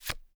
card_pickup.m4a